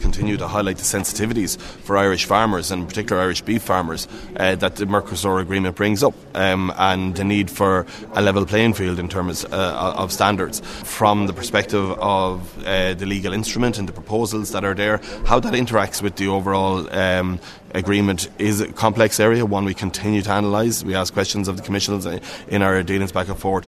Minister Martin Heydon says he will work with other countries that oppose the agreement……….